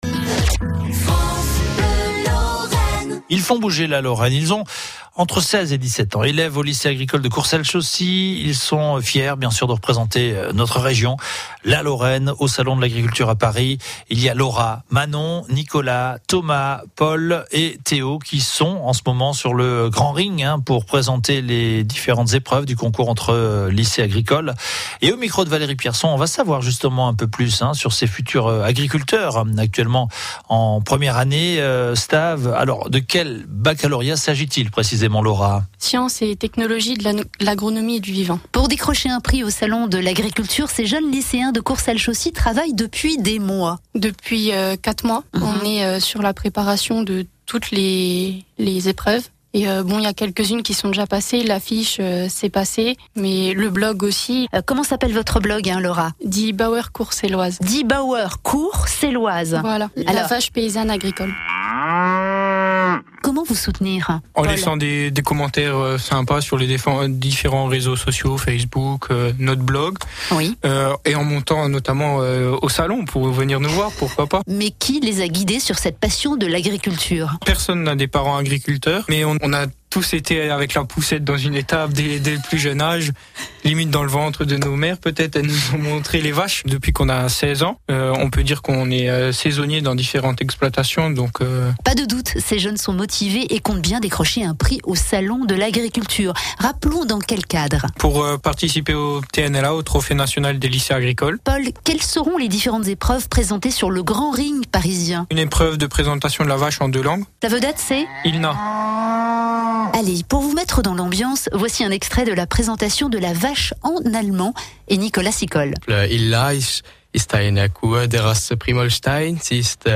Notre équipe a eu l'honneur de passer sur notre radio régionale " France Bleu Lorraine " dans la rubrique " Ils font bouger la Lorraine " entre midi.